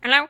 女孩语音“Hello（你好）”
描述：女孩发出的“Hello”语音。
标签： 你好 语音 女孩
声道立体声